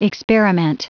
Prononciation du mot experiment en anglais (fichier audio)
Prononciation du mot : experiment